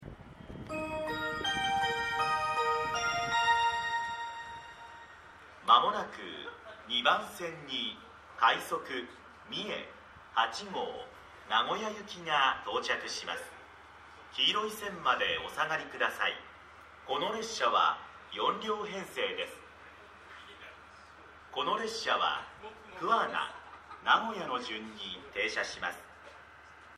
この駅では接近放送が設置されています。
接近放送快速みえ8号　名古屋行き接近放送です。